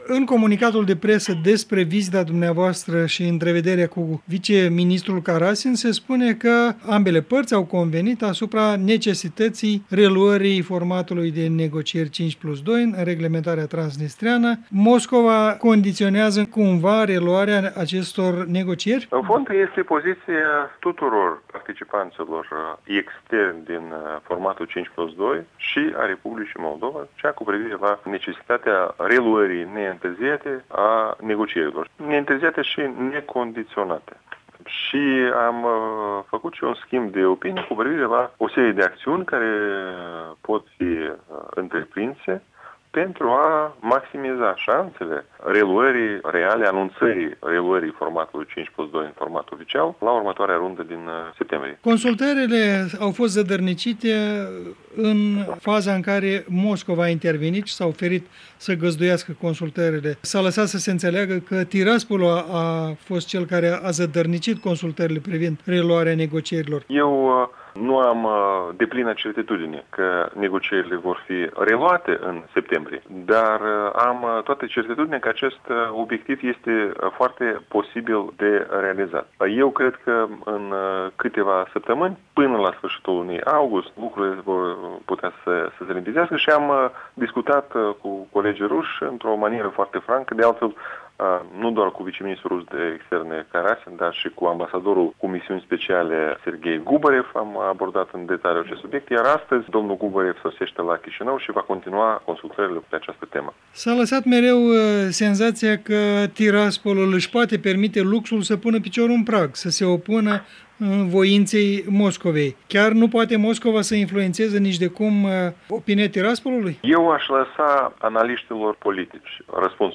Un interviu cu viceministrul de externe Andrei Popov